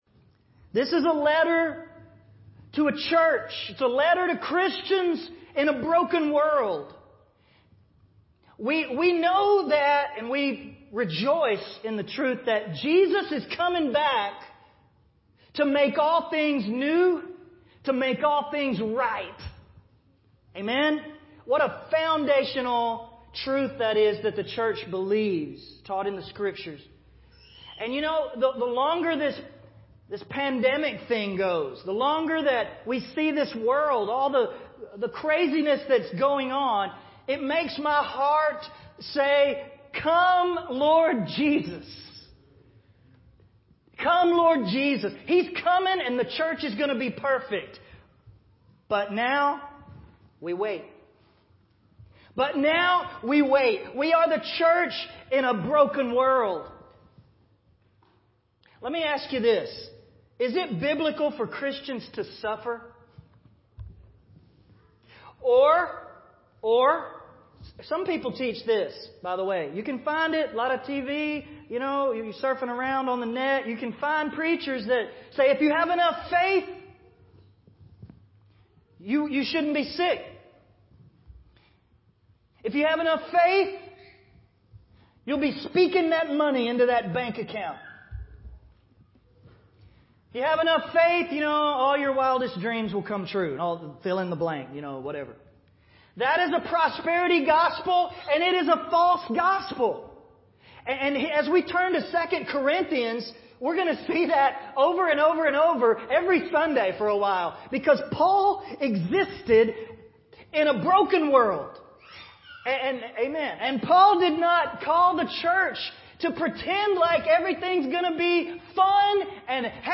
Christians and Suffering – 2Cor Study 2